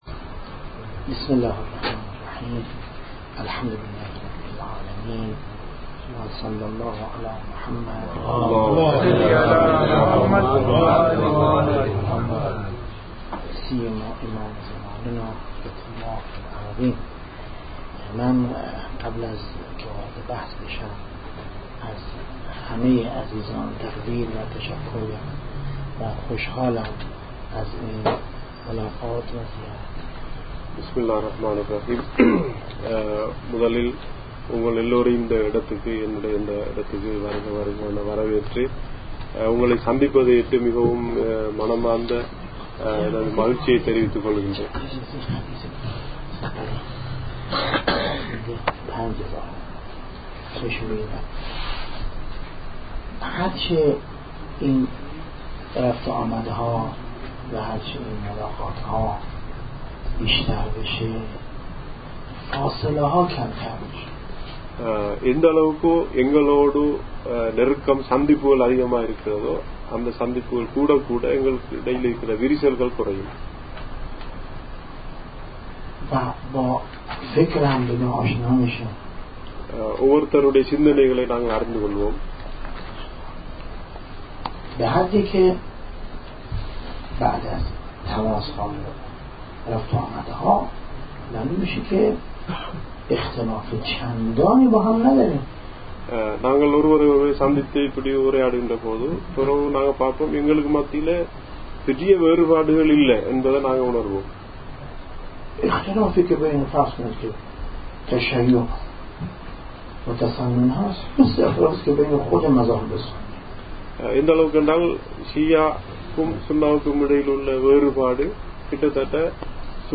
دیدار جمعی از نخبگان فرهنگی از اهل سنت سریلانکا